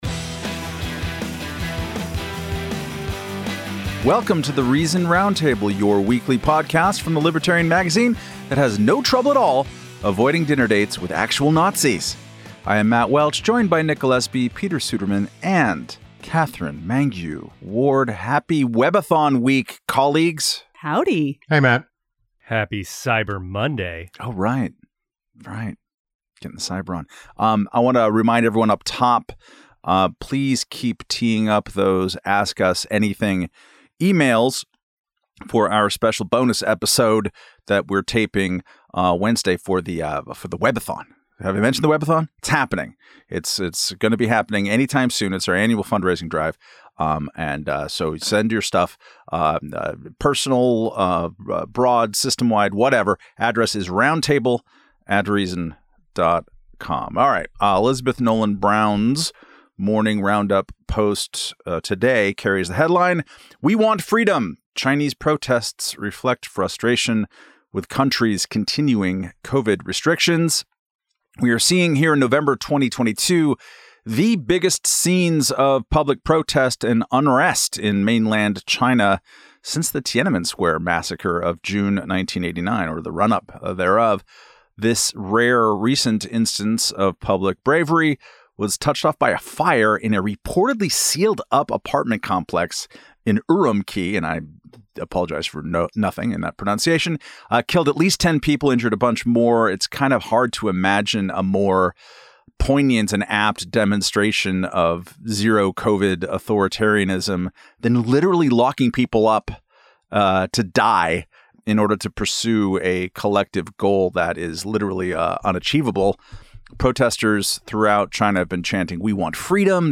In this week's The Reason Roundtable, the editors discuss the latest protests against "zero-Covid" policies in China as well as other examples of lingering public health authoritarianism.